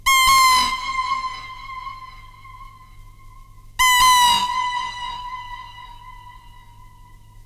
Couldn’t get the bird to squeal nearly as angrily.
Sounds a bit like a baby dolphin lost in an undersea cavern :pleading_face:
Great retro video game explosion sound actually!